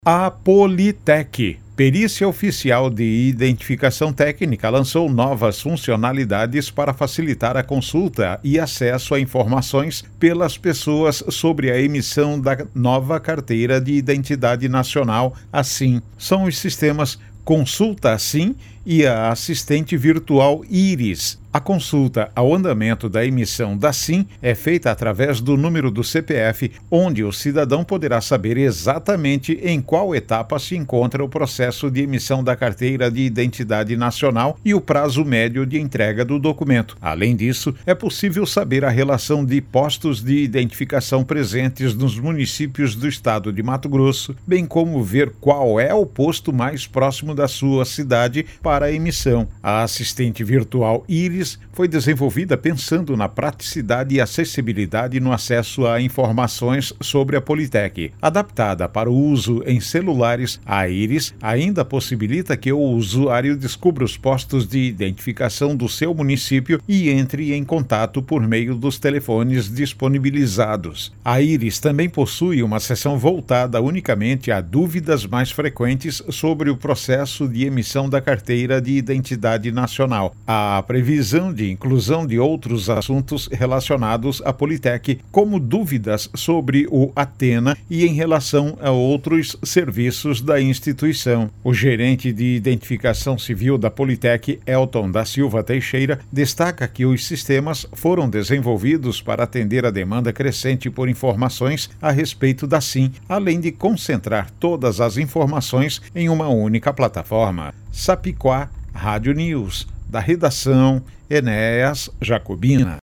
Boletins de MT 18 dez, 2025